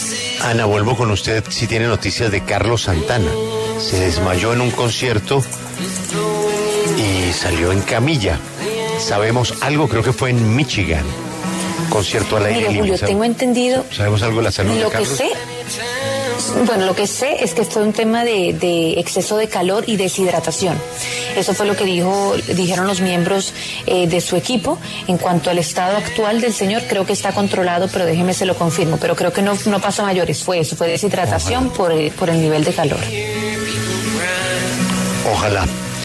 El guitarrista Carlos Santana se desmayó cuando llevaba unos 40 minutos de concierto en un auditorio al aire libre.
Aproximadamente unos veinte minutos después, el artista fue retirado del escenario en camilla, un momento que fue captado por los teléfonos de los asistentes, que lo compartieron en las redes y en ellos se pude ver a Santana saludar con la mano.